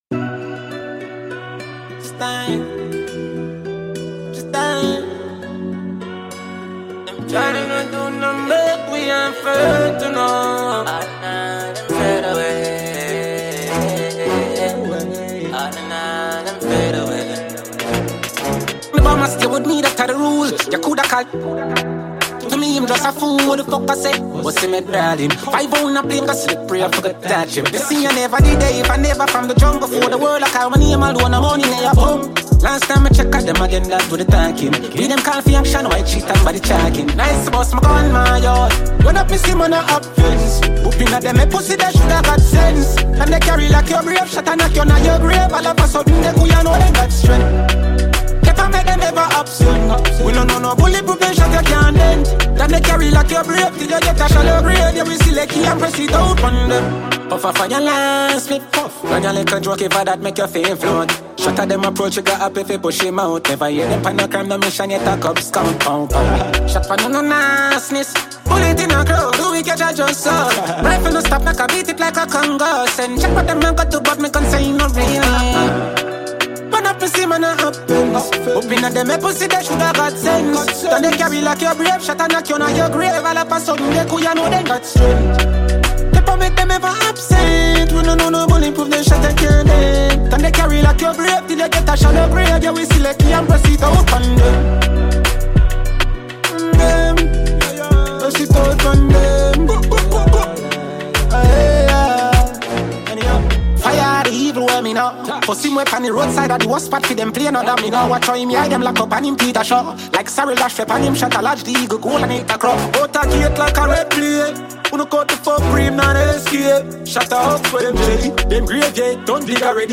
Top Jamaican dancehall star